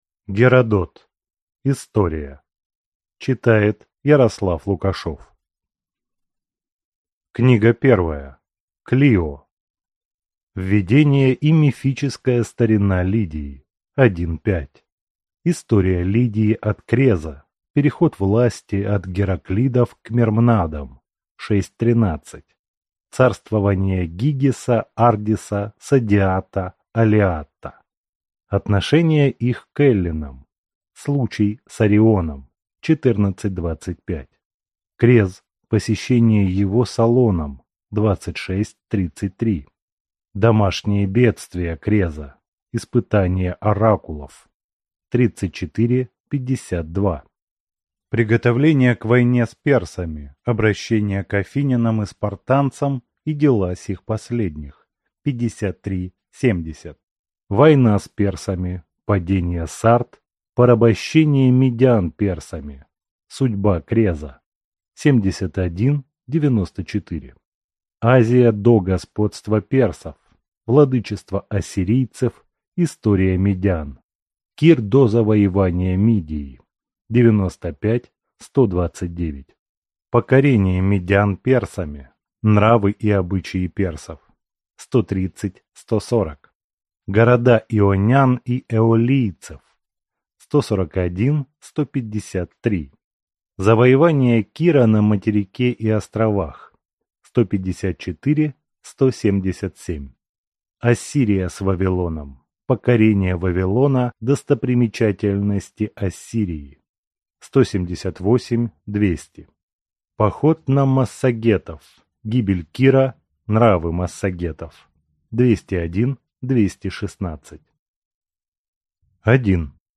Aудиокнига История